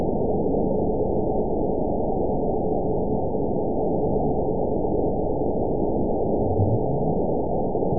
event 921703 date 12/16/24 time 23:15:33 GMT (10 months, 1 week ago) score 9.59 location TSS-AB04 detected by nrw target species NRW annotations +NRW Spectrogram: Frequency (kHz) vs. Time (s) audio not available .wav